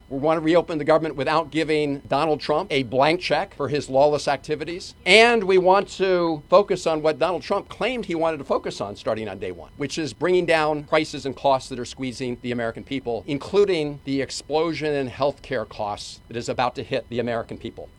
The Maryland federal Democratic delegation gathered Wednesday afternoon for a press conference following another failed vote to reopen the government. The lawmakers said they were eager to come to an agreement with Republicans to end the government shutdown, but Senator Chris Van Hollen said certain issues need to be dealt with…